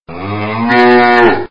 دانلود آهنگ گاو از افکت صوتی انسان و موجودات زنده
جلوه های صوتی
دانلود صدای گاو از ساعد نیوز با لینک مستقیم و کیفیت بالا